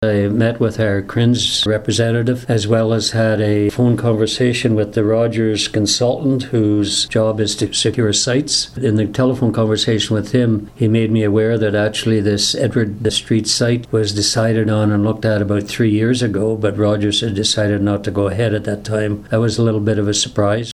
At Arnprior Council Monday, Mayor Walter Stack updated the community on discussions with (and about) Rogers Communications- which has apparently had its eyes on a piece of property at Edward and William Streets for the past several years.